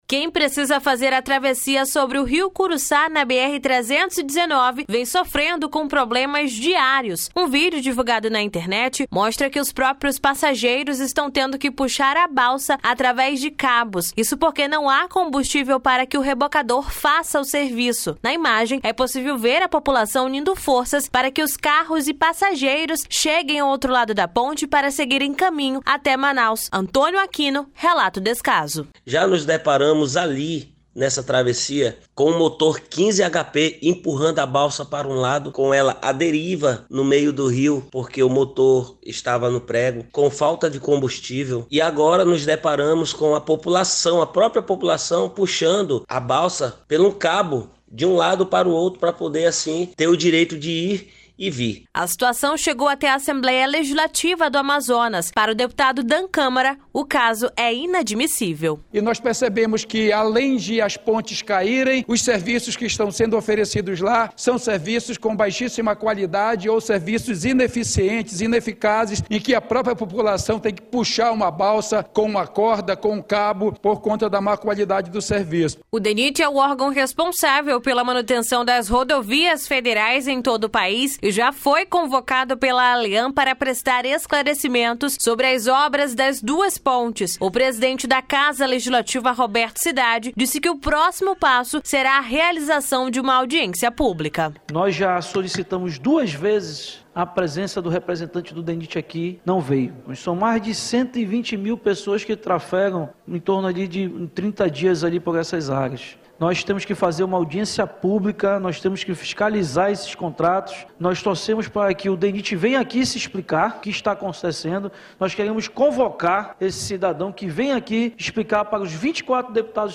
Reportagem
A situação chegou até a Assembleia Legislativa do Amazonas. Para o deputado Dan Câmara a situação é inadmissível: (Ouça)
O presidente da Casa Legislativa, Roberto Cidade, disse que o próximo passo será realização de uma Audiência Pública. (Ouça)